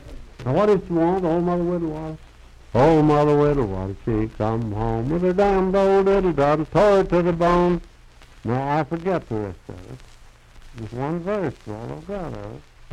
Unaccompanied vocal music
Bawdy Songs
Voice (sung)
Marion County (W. Va.), Fairview (Marion County, W. Va.)